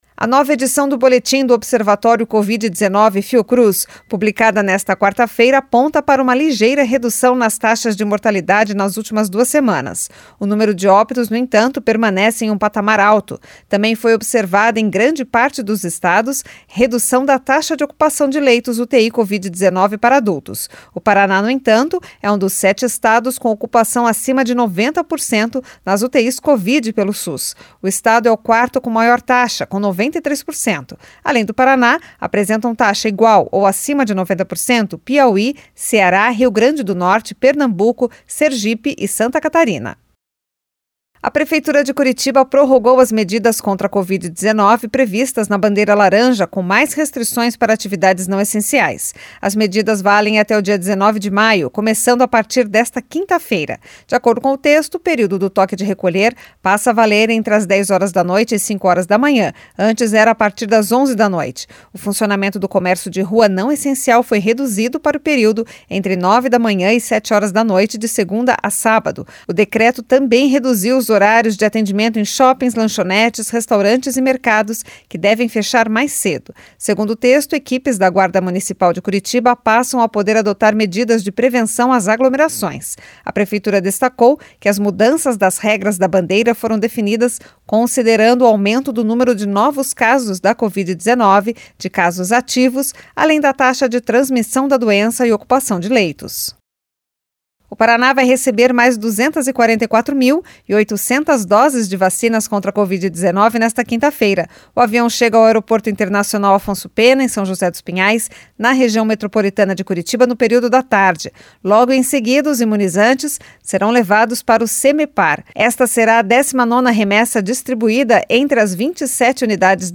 Giro de Notícias Manhã SEM TRILHA